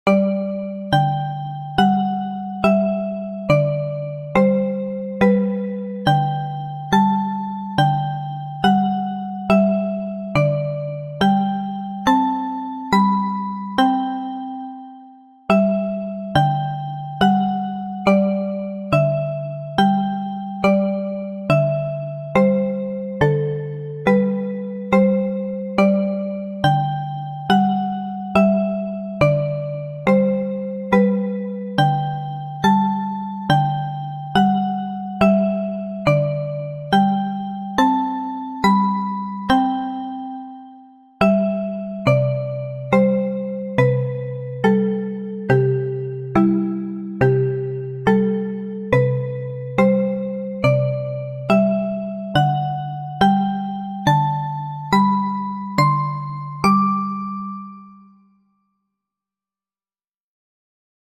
BPM70 オルゴール